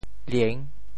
吝（恡） 部首拼音 部首 口 总笔划 7 部外笔划 4 普通话 lìn 潮州发音 潮州 liêng6 文 中文解释 吝 <动> (形声。
lieng6.mp3